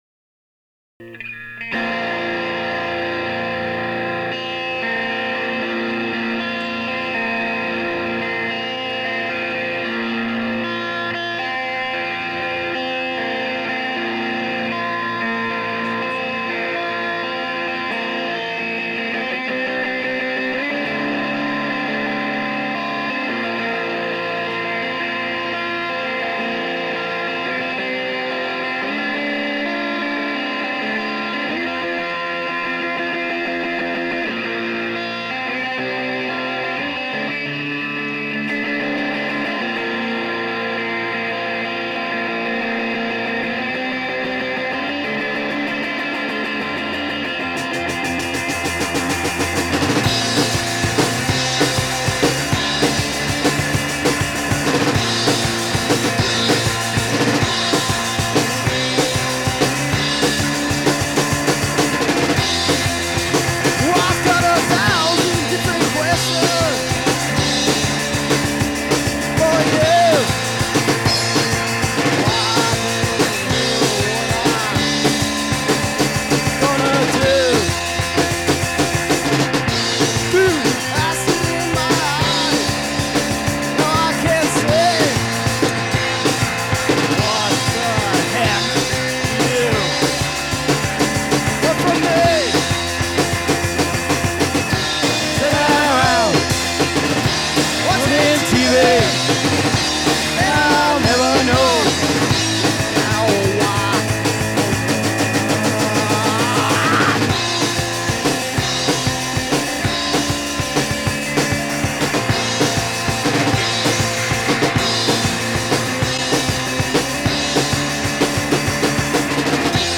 Guitar, vocals, percussion
Bass
Drums, backing vocals